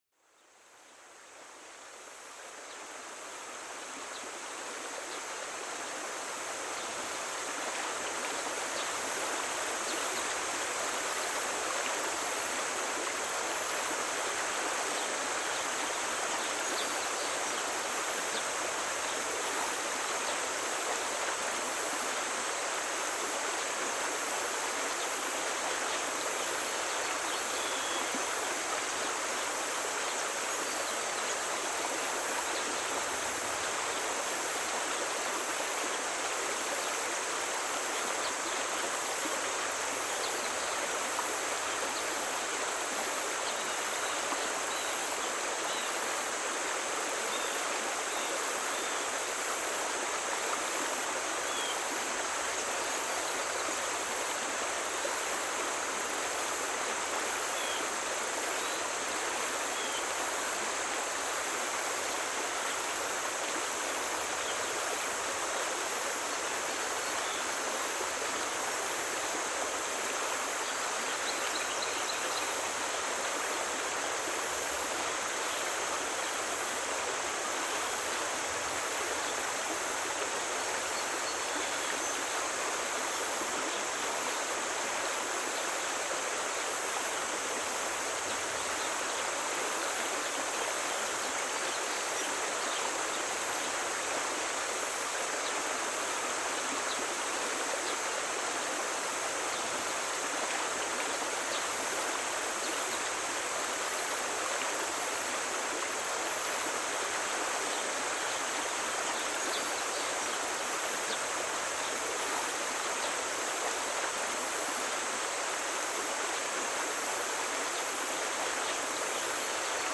Le murmure d'une rivière et le chant des oiseaux pour apaiser l'esprit